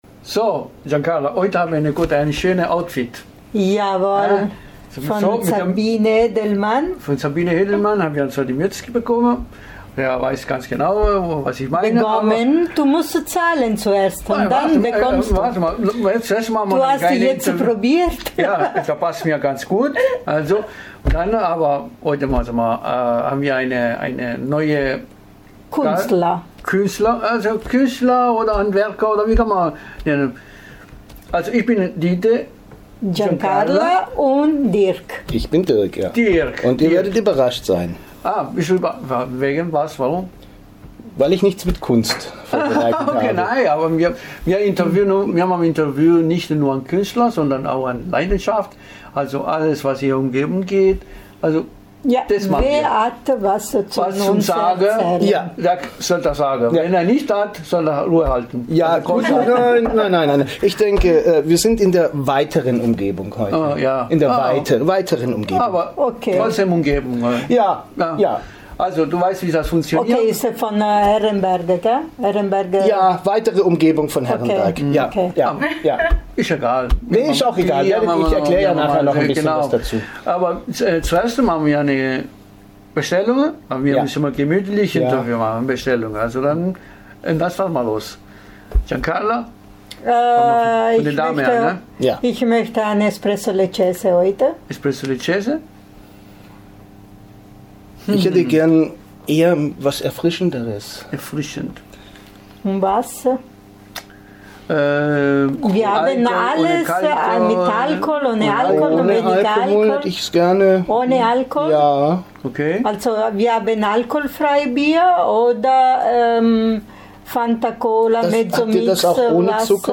DEN REST KÖNT IHR IM INTERVIEW HÖREN, DAS IN DER BESCHREIBUNG GETEILT IST.